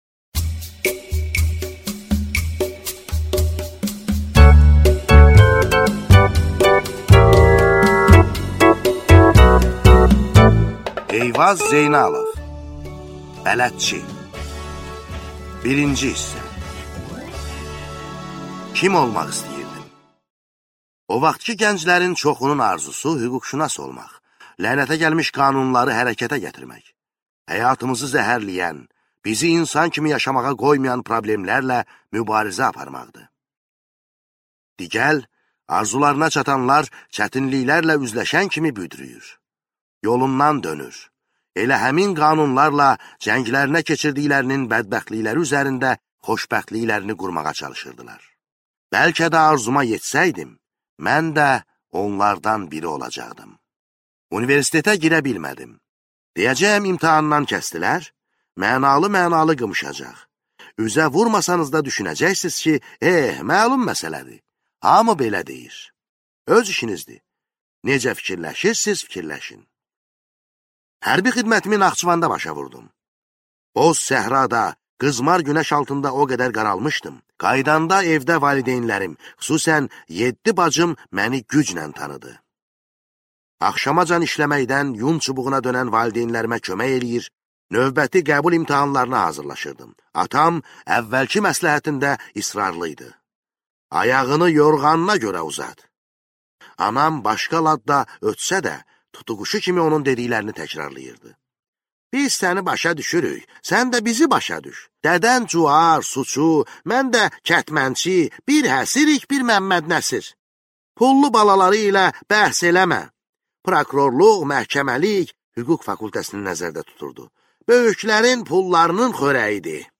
Аудиокнига Bələdçi | Библиотека аудиокниг